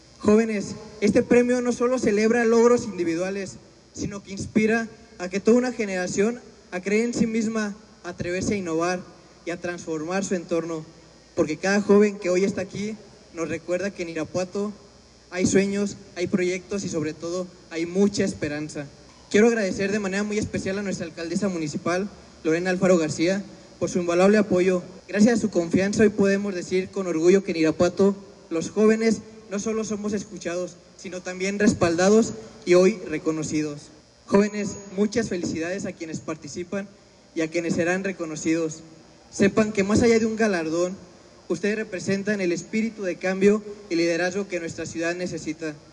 AudioBoletines
• Se lleva a cabo la entrega del Premio Municipal de la Juventud 2025
Gerardo Oñate Gutiérrez, director del Imjuvi